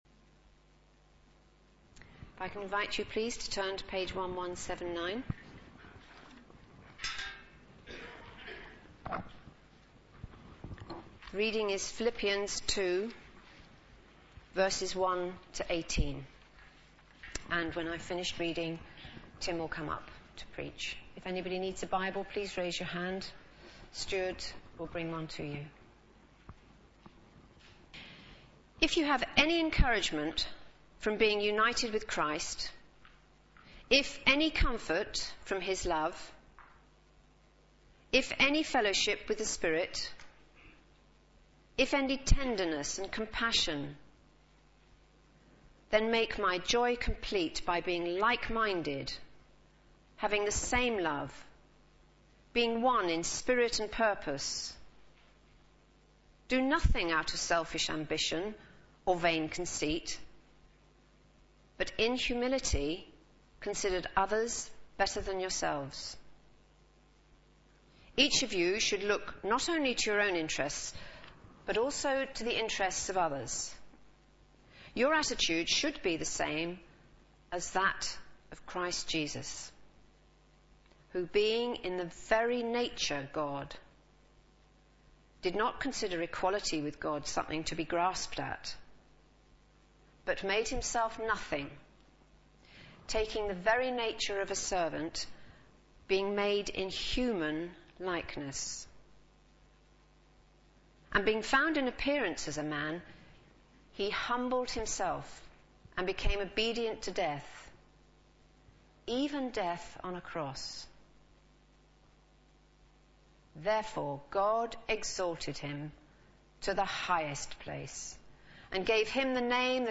Media for Sunday Service on Sun 05th Feb 2012 10:00
Series: The Uniqueness of Jesus Christ Theme: Christ as the Servant of God Sermon